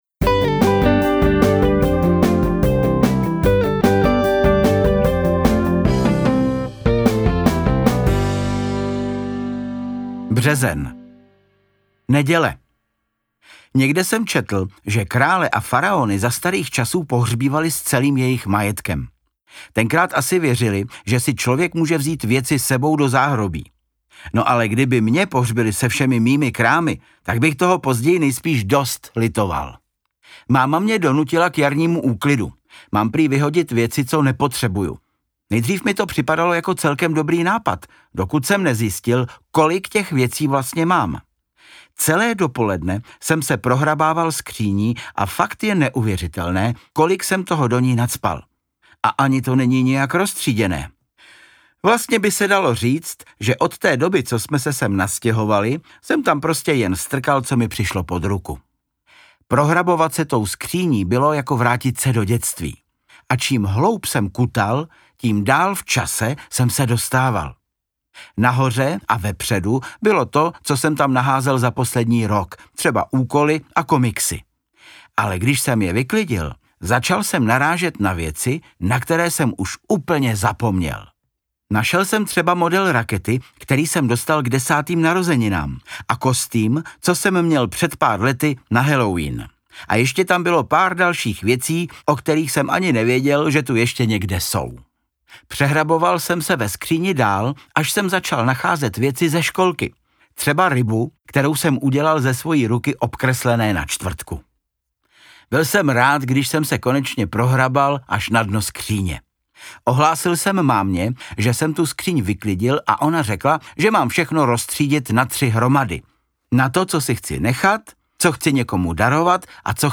Interpret:  Václav Kopta